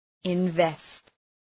Shkrimi fonetik {ın’vest}